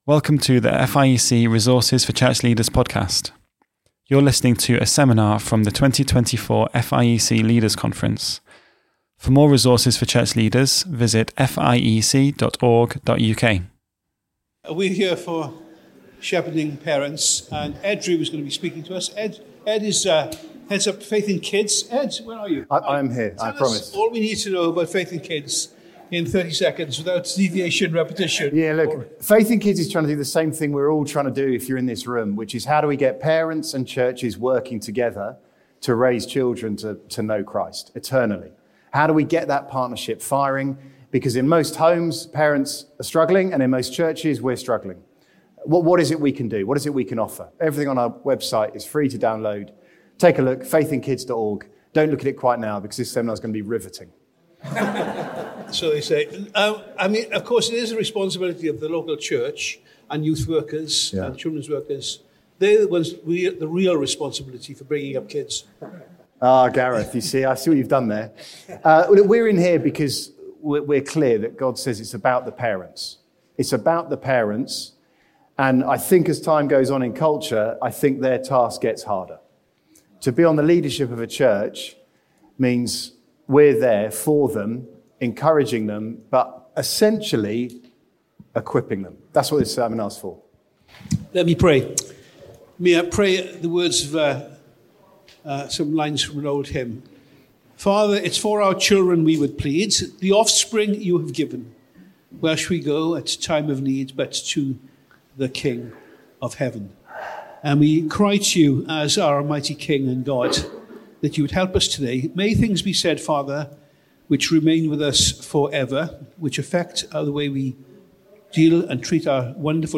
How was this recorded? Series: Leaders' Conference 2024